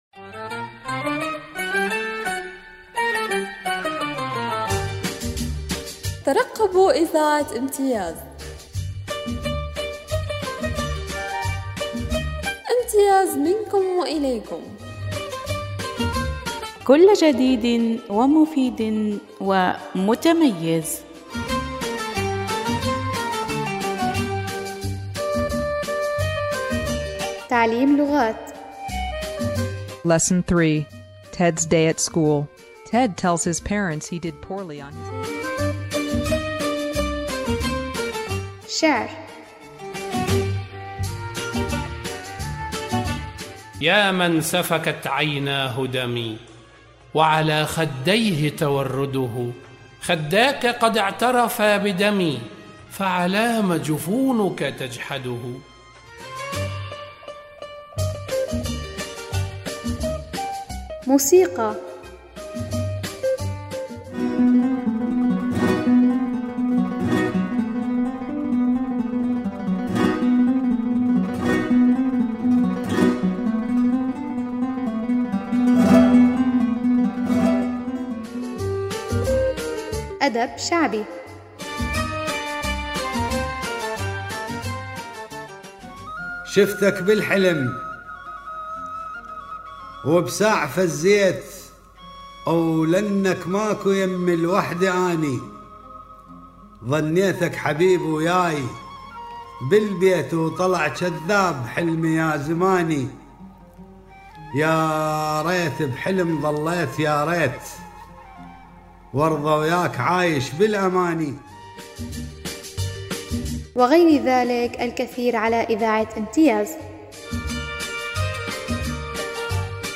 إعلان-ترويجي-عن-إذاعة-امتياز.mp3